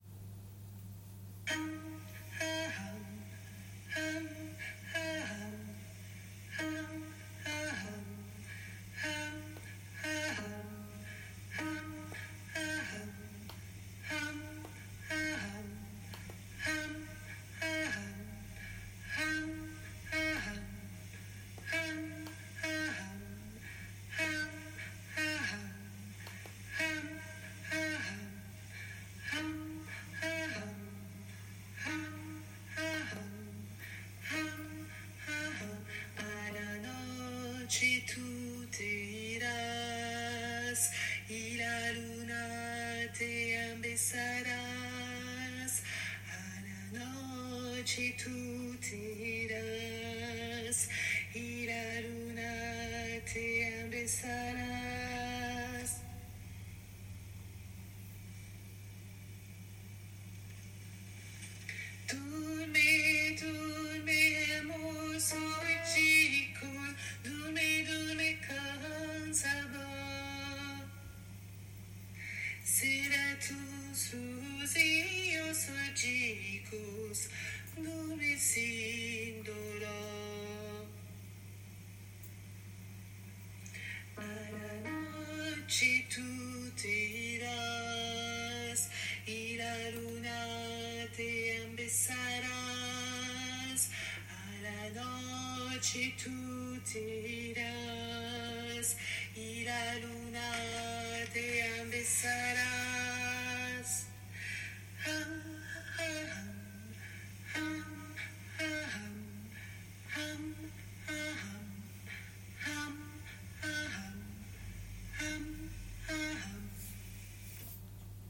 - berceuses juive séfarade
MP3 versions chantées
Hommes